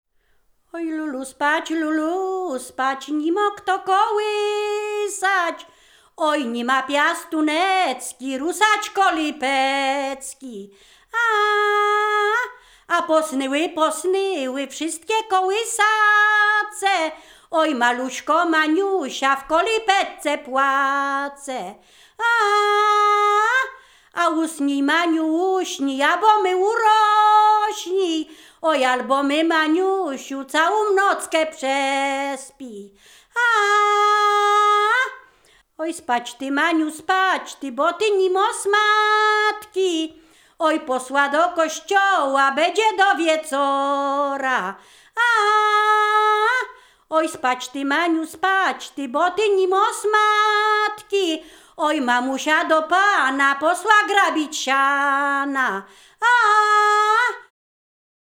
województwo mazowieckie, powiat przysuski, gmina Rusinów, wieś Brogowa
kołysanka folklor dziecięcy